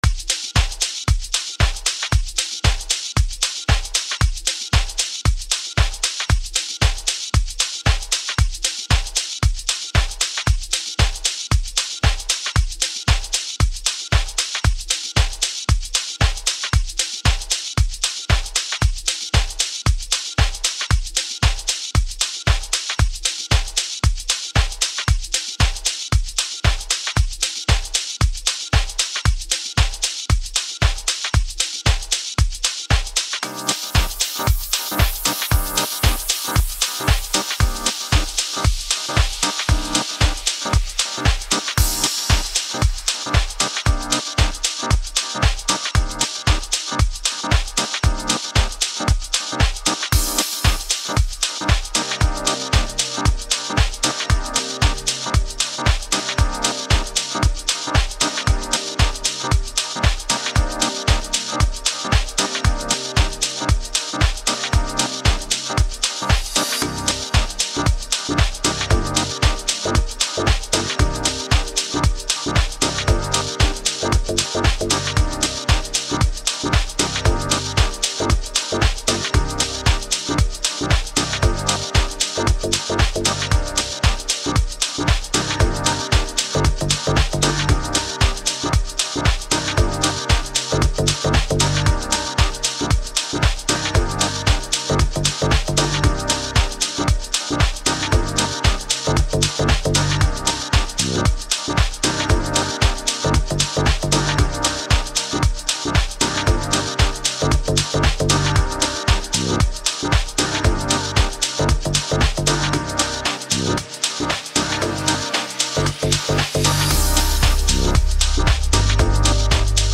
addictive, infectious and more that just a melody beat